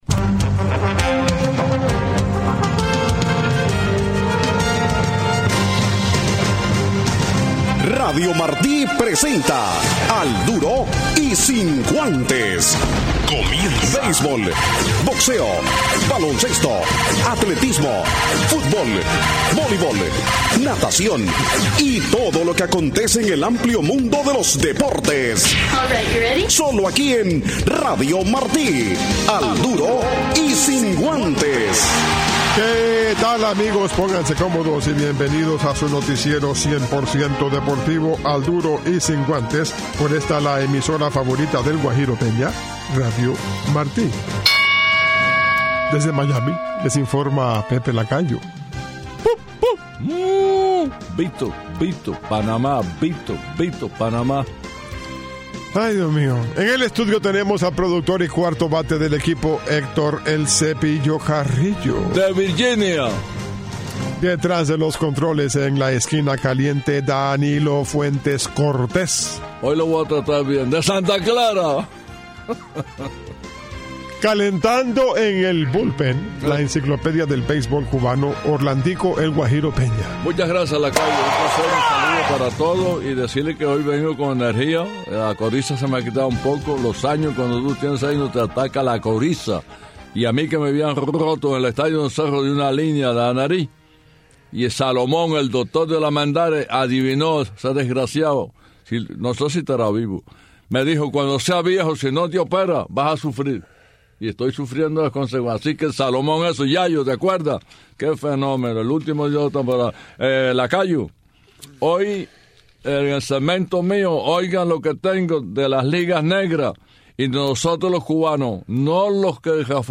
tuvimos dos entrevistas en el programa